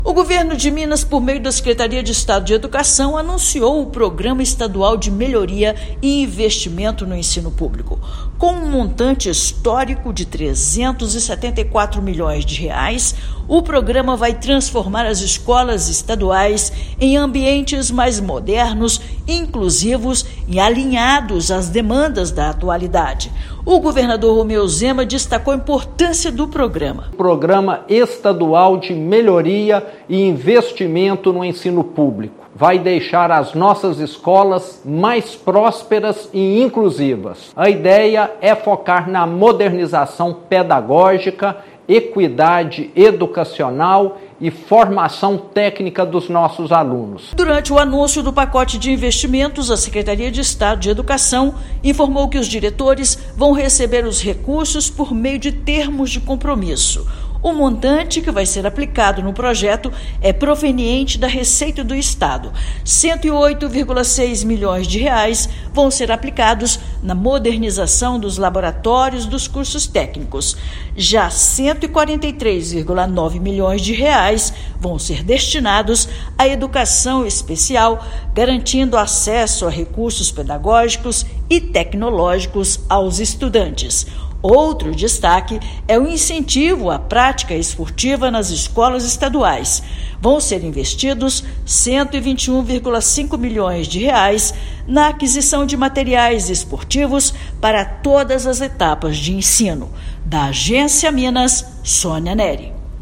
Fechando o ano em que a educação do Estado teve como prioridade a promoção do desenvolvimento dos estudantes, serão destinados R$ 374 milhões para inclusão educacional, incentivo ao esporte e qualificação técnica. Ouça matéria de rádio.